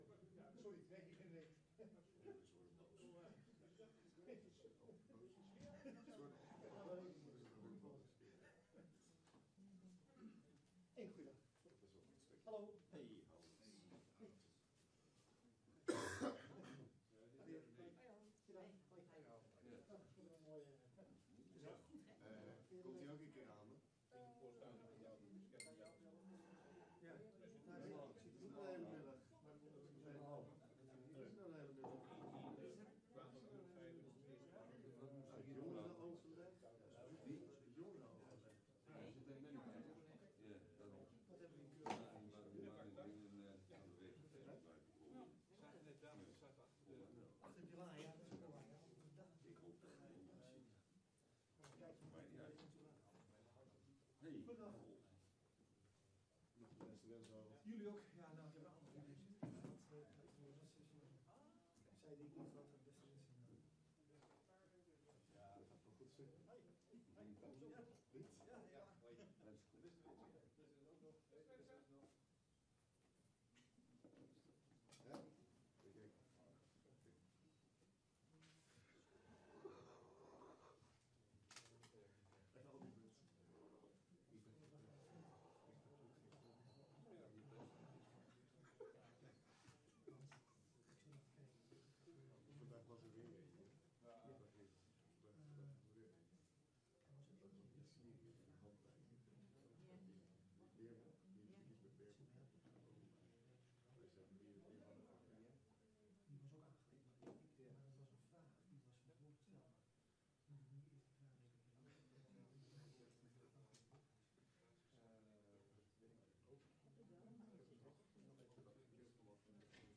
Download de volledige audio van deze vergadering
Locatie: Commissiekamer